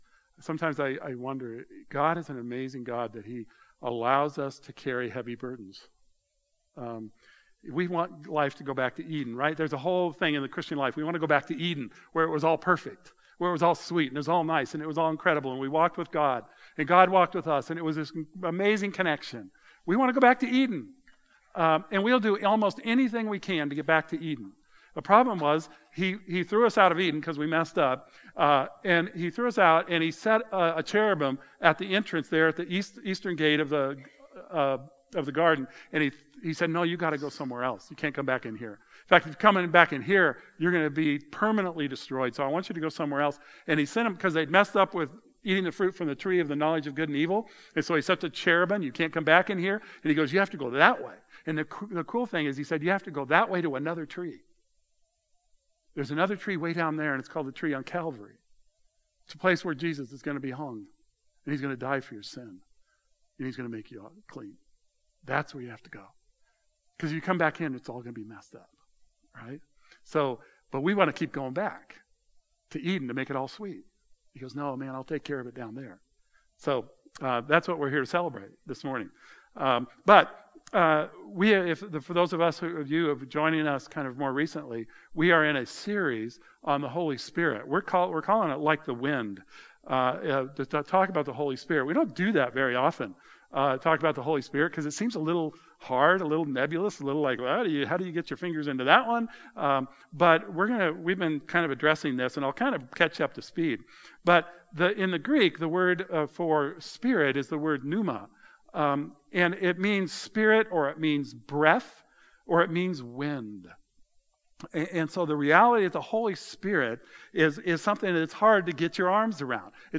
This morning's message focuses on the passage John 16:4b-15 and looking at who the Holy Spirit is to us and why He is needed.